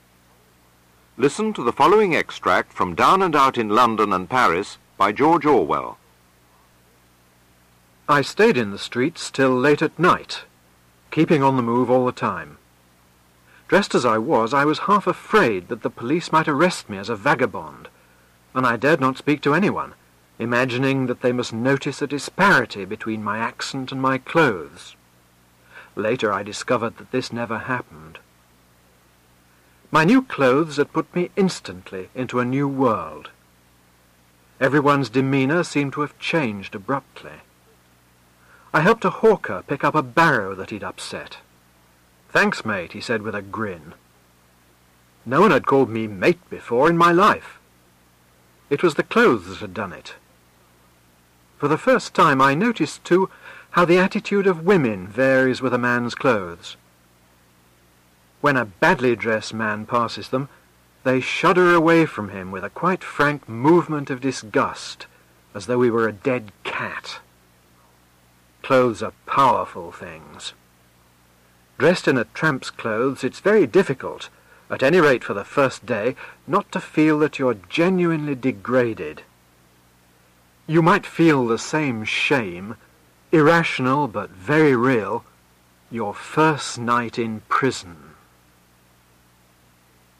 Audio Plosives Down in Paris and London by Orwell.mp3